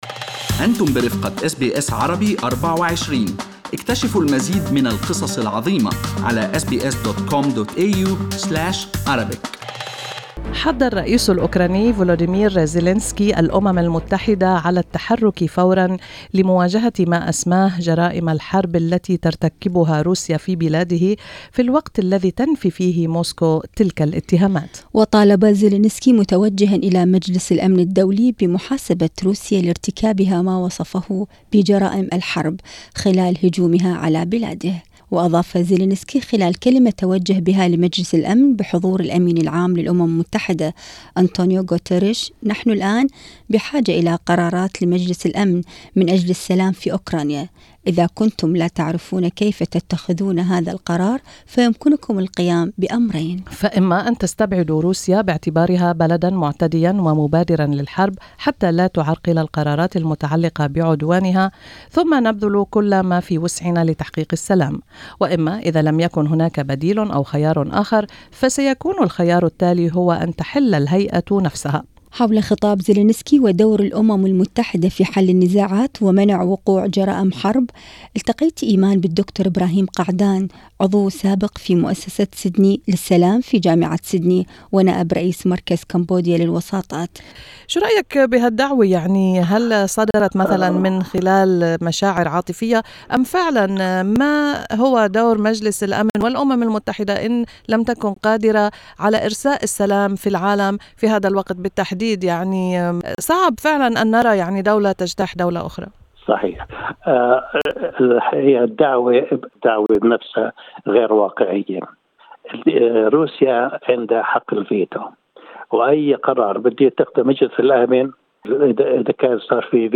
وفي حديث مع SBS Arabic24